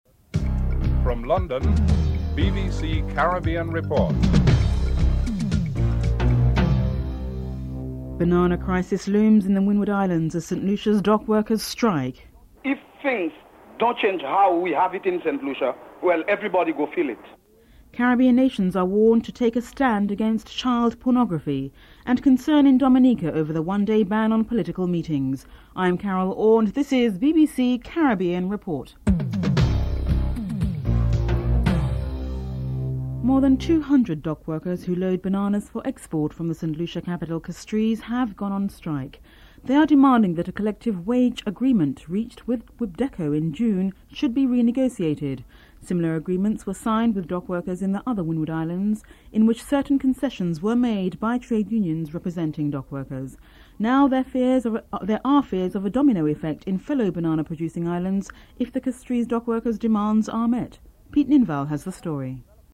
The British Broadcasting Corporation
1. Headlines (00:00-00:33)